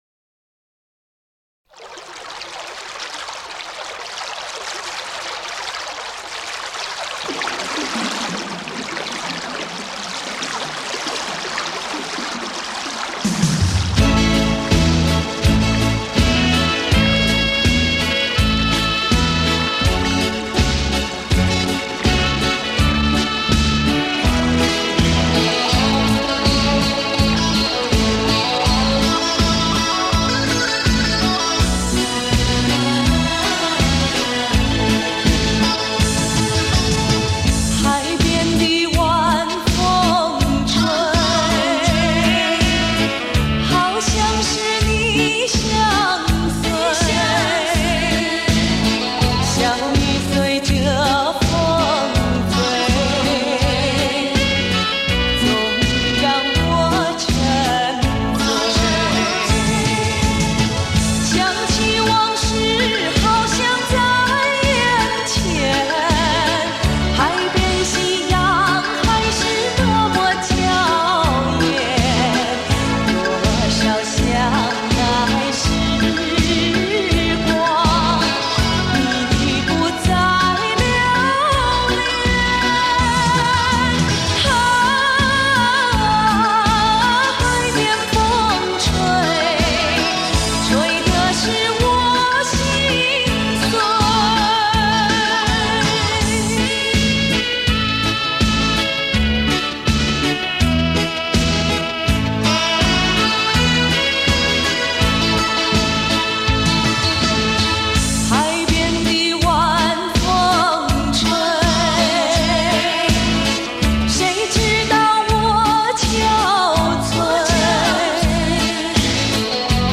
日本KORG公司 提供音源
她的声音宽厚、高亢，深沉通畅；她的演唱气度雍容，婉转自如，具有东方女性温柔、典雅的魅力。